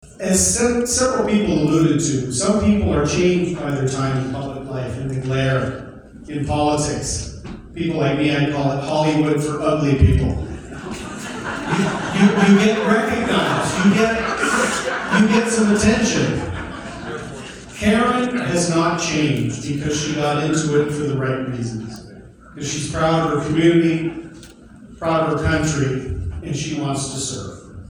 A surprise guest at the celebration was former Conservative Party of Canada leader Erin O’Toole.